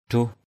/d̪ruh/ 1.